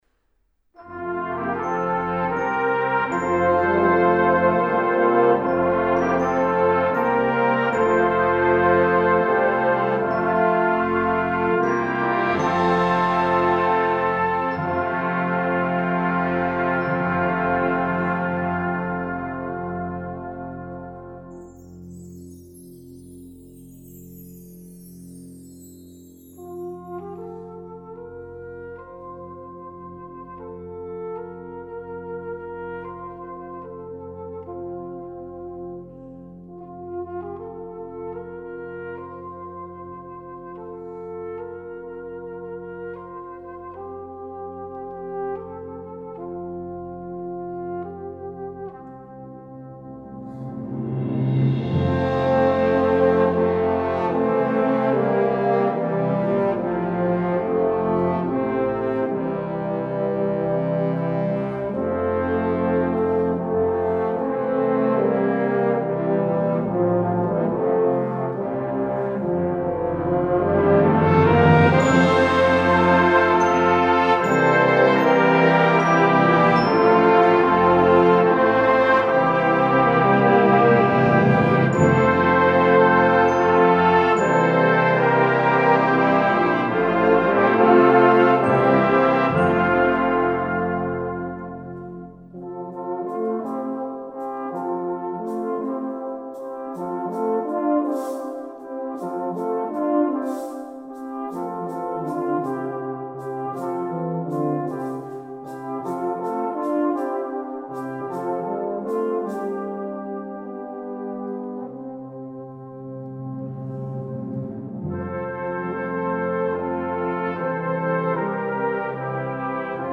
Komponist: Traditionell
Gattung: für Blasorchester
Besetzung: Blasorchester